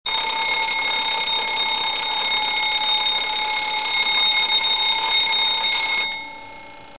Sveglia meccanica suono campanella
Suono di campanella, tipo sveglia meccanica o campanello di allarme.
ALARMCLOCK.mp3